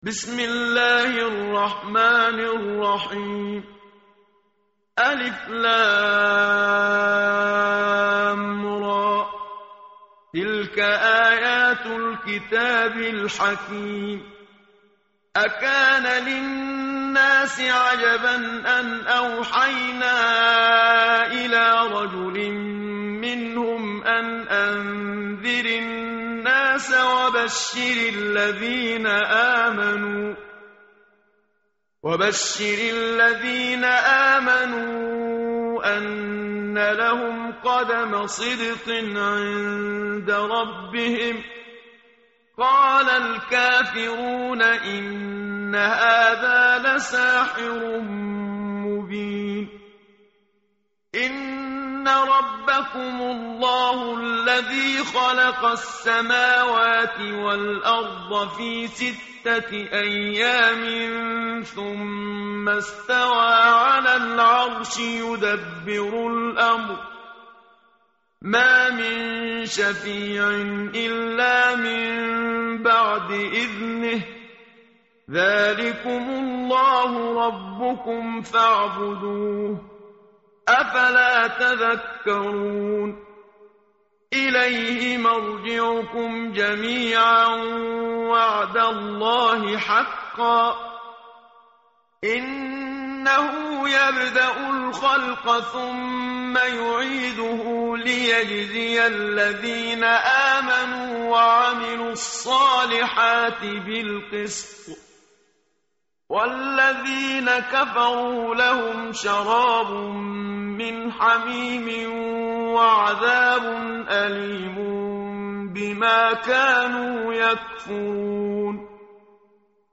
tartil_menshavi_page_208.mp3